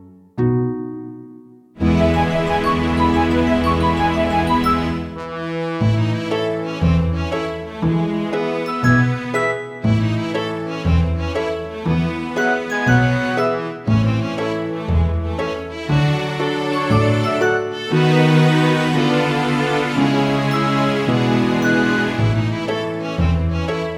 With Female Singer Soundtracks 2:16 Buy £1.50